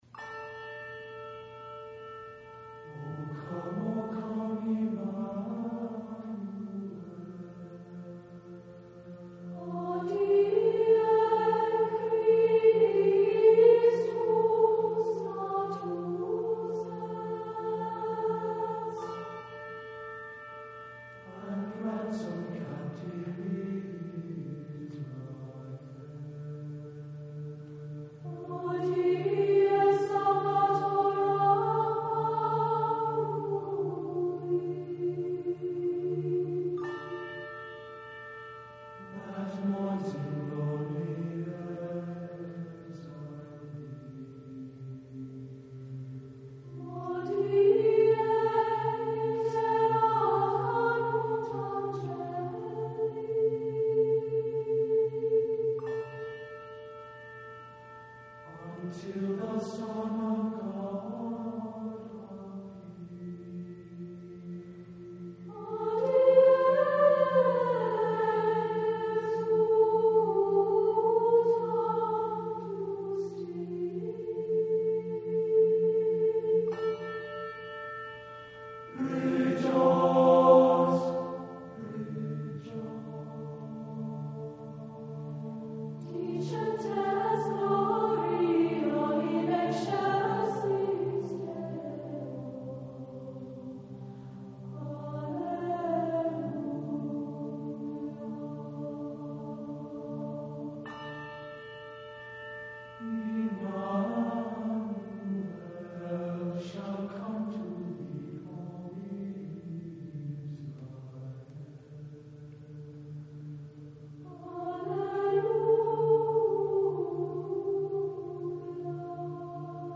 SATB